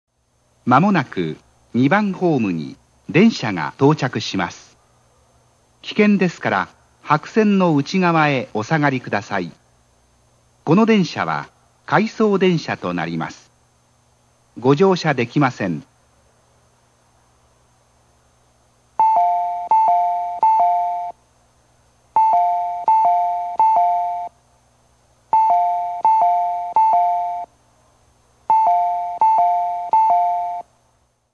大阪モノレール線旧駅放送